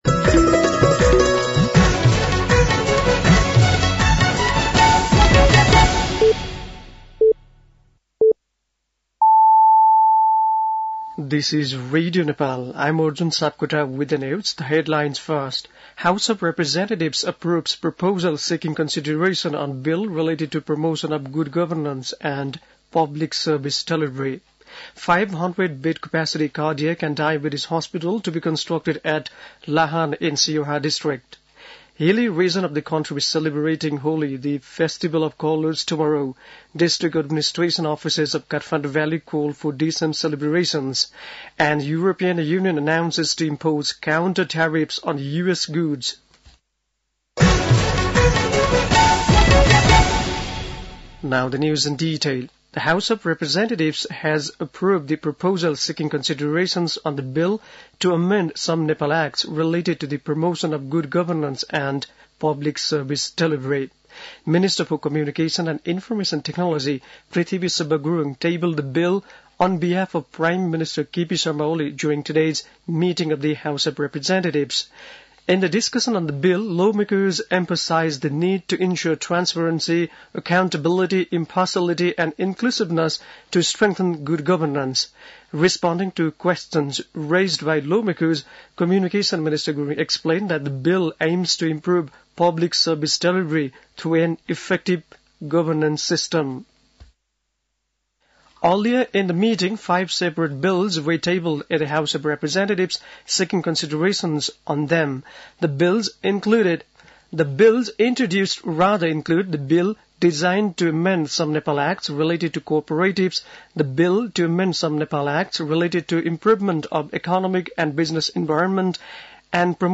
बेलुकी ८ बजेको अङ्ग्रेजी समाचार : २९ फागुन , २०८१
8-pm-news-2.mp3